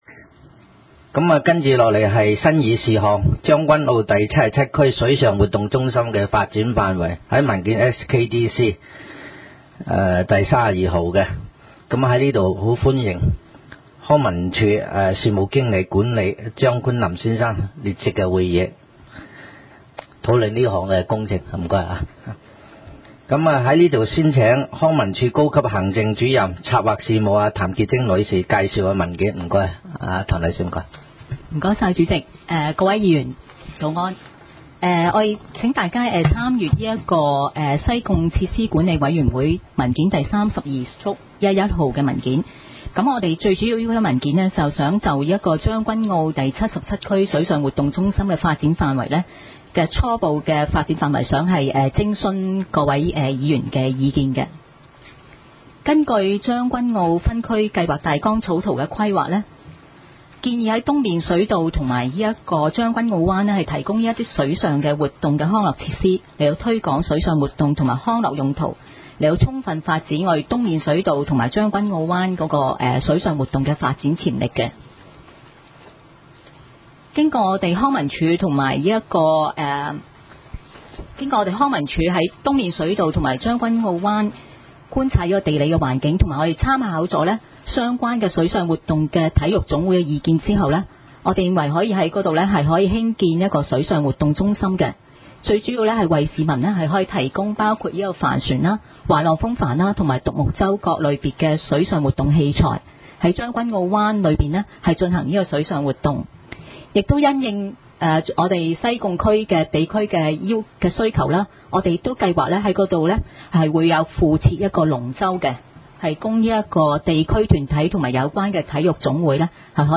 西貢區議會第 六次會議